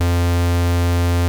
MOOG SQUARE.wav